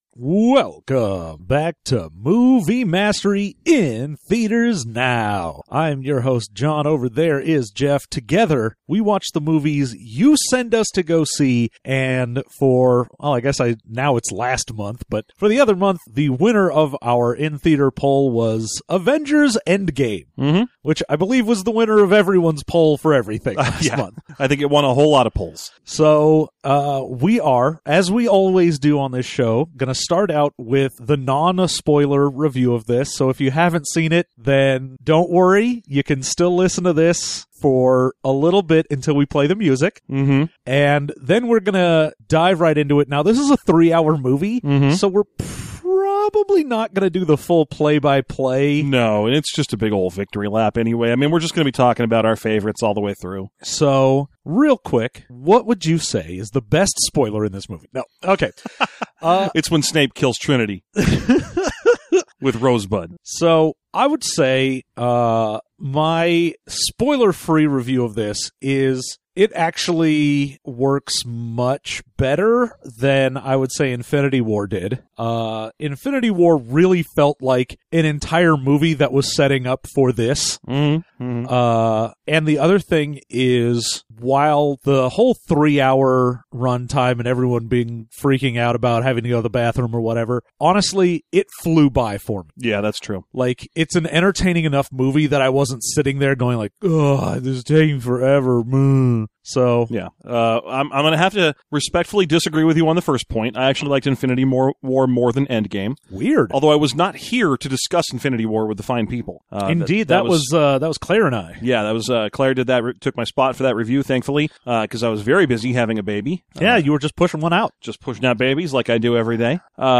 It's an extra long episode about an extra long movie. Over 20 movies in the making and now two idiots from the internet get to nitpick minor quibbles.